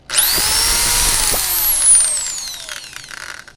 drill_use.ogg